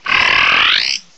The cries from Chespin to Calyrex are now inserted as compressed cries
chewtle.aif